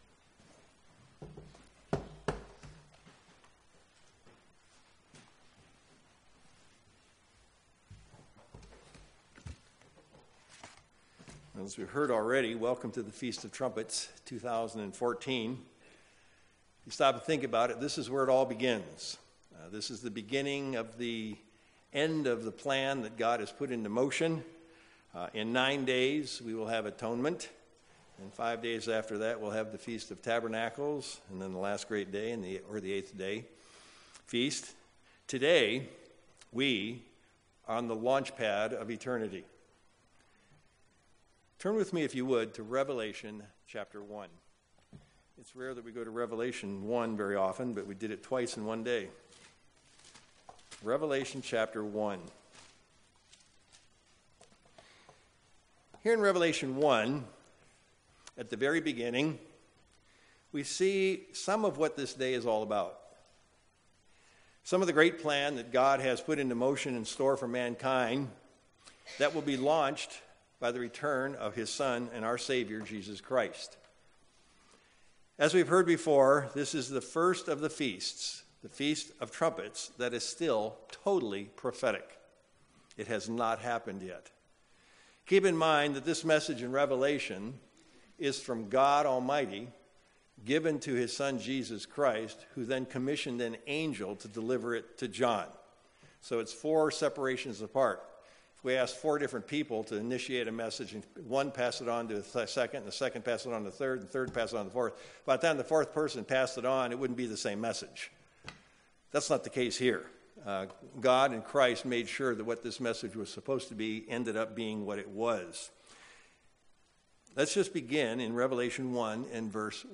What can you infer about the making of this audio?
Given in Sacramento, CA